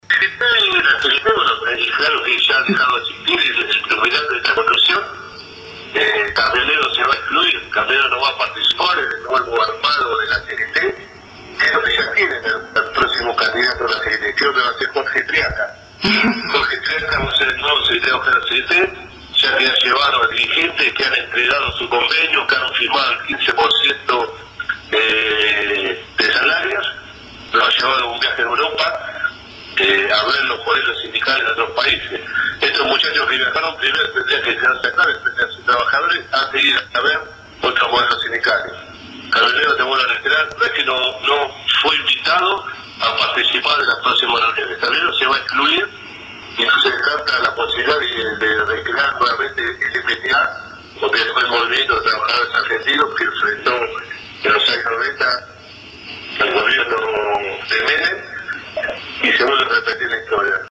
Pablo Moyano, audio gentileza FM «La Patriada»